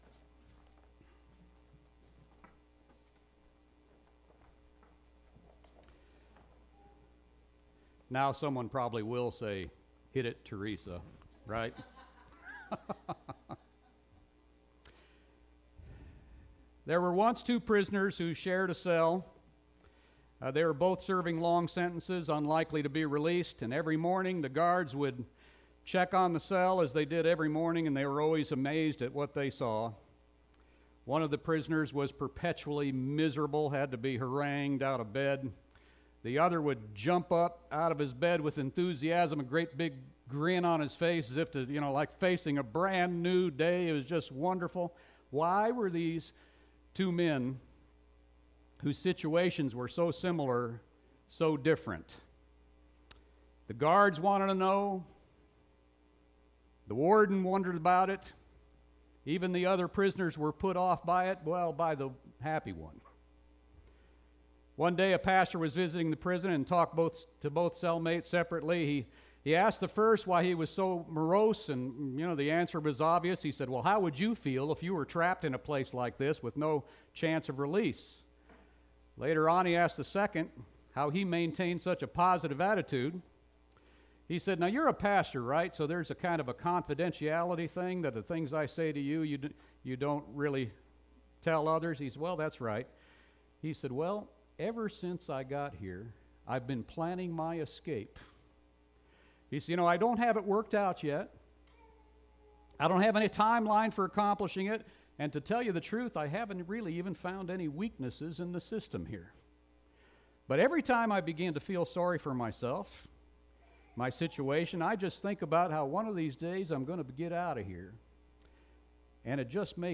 Sermons and Lessons - Faith Bible Fellowship